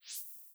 LittleSwoosh3.wav